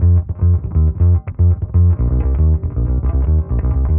Index of /musicradar/dusty-funk-samples/Bass/120bpm
DF_PegBass_120-F.wav